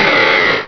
Cri de Vigoroth dans Pokémon Rubis et Saphir.